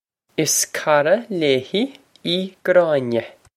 Pronunciation for how to say
Iss kor-a lay-he ee Graw-neh.
This is an approximate phonetic pronunciation of the phrase.